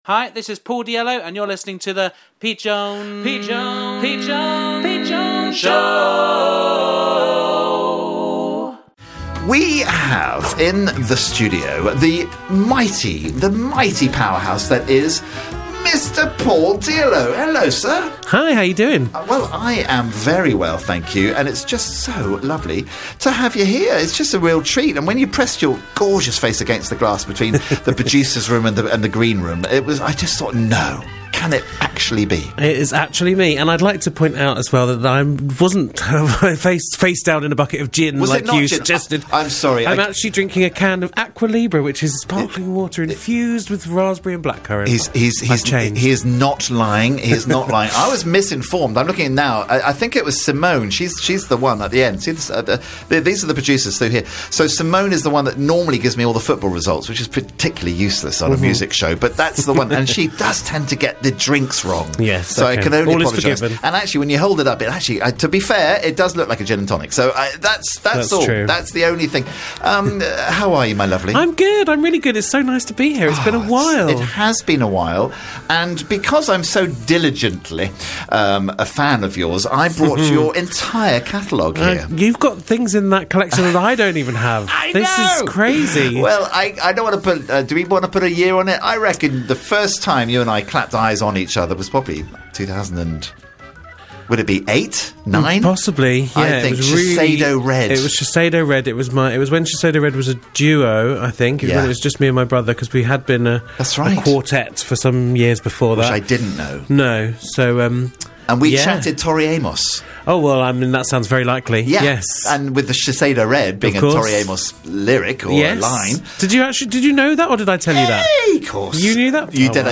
Live chat